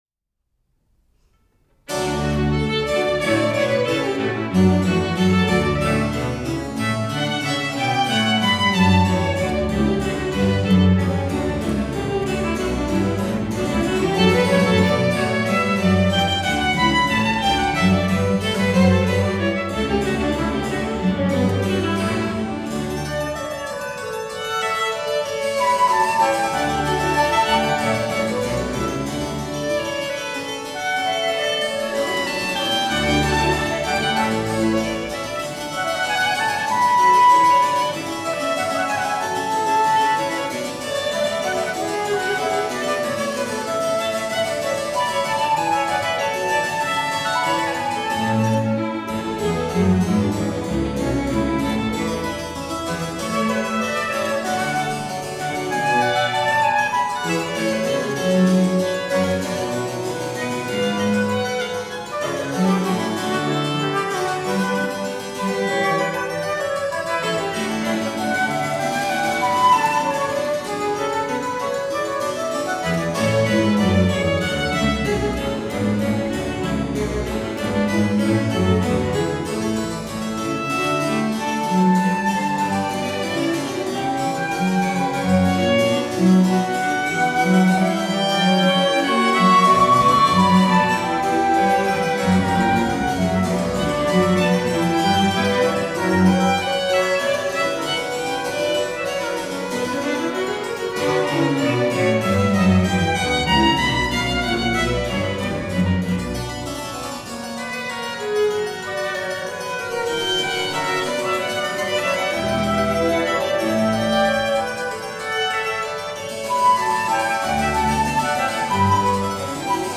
Orchestra d'Archi